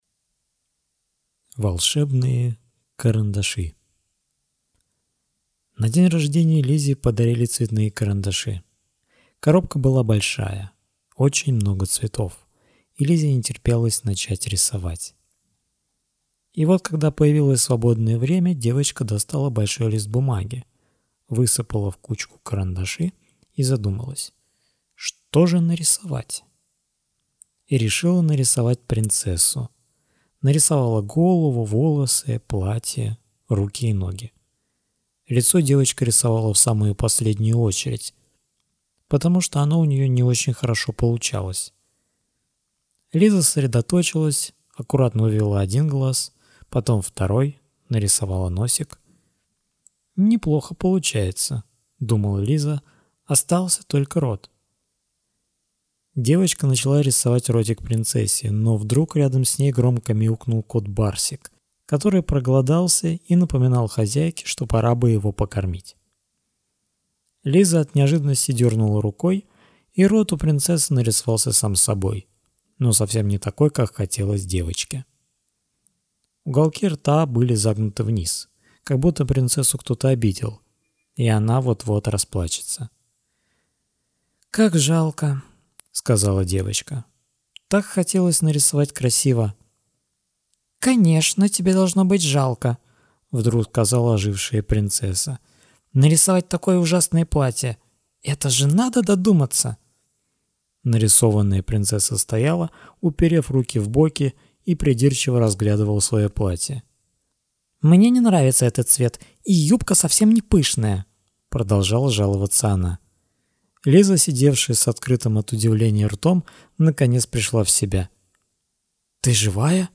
Сказки, рассказы в аудиоформате🌟